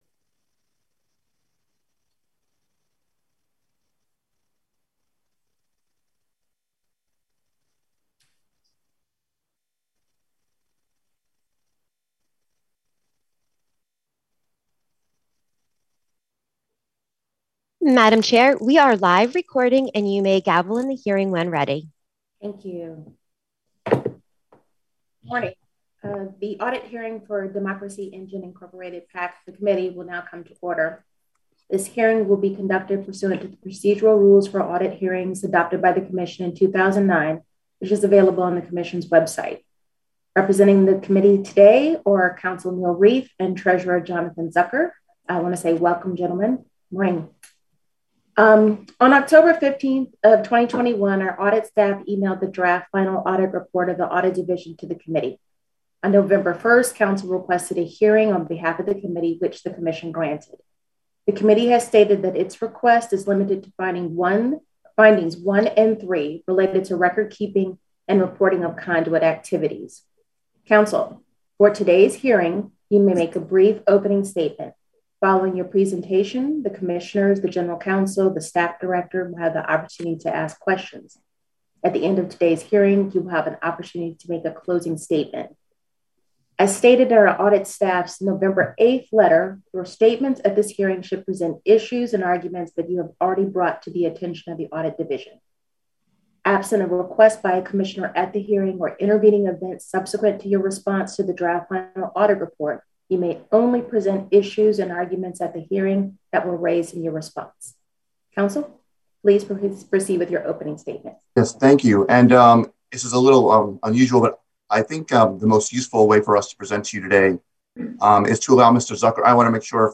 December 2, 2021 audit hearing